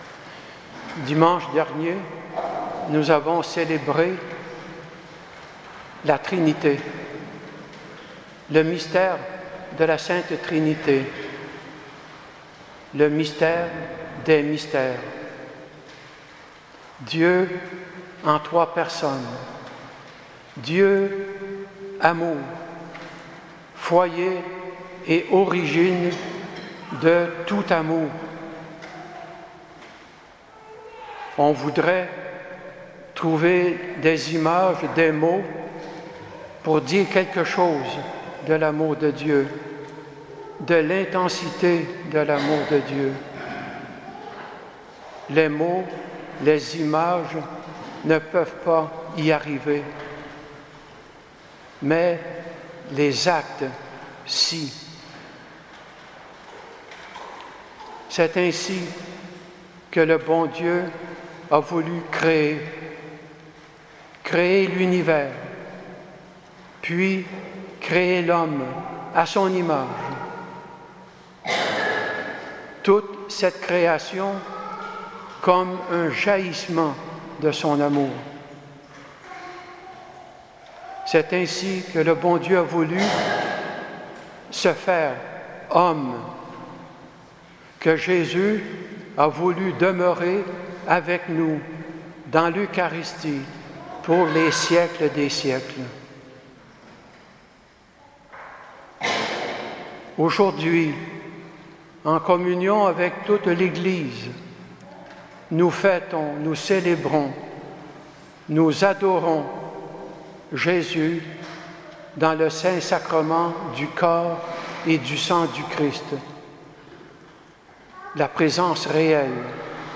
Homélie